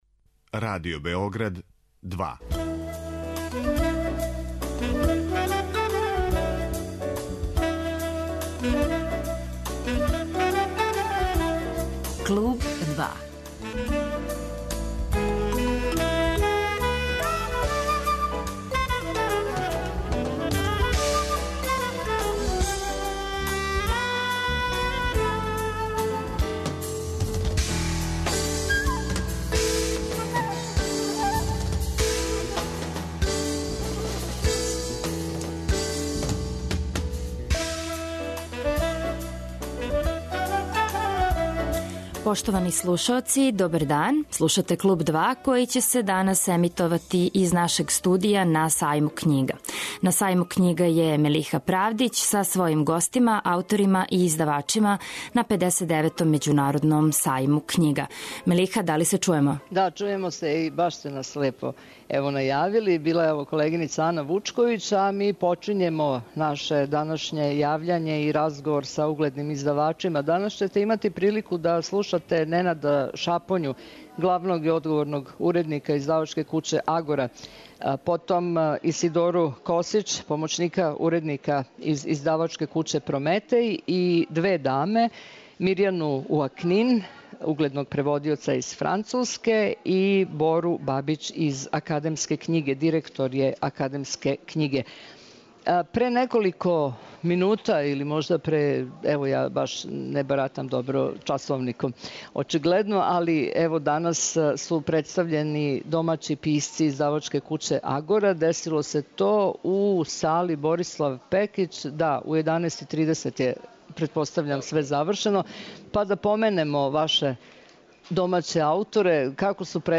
Гости 'Клуба 2' су аутори и издавачи на 59. међународном београдском сајму књига.
Емисија се емитује из студија на Сајму књига.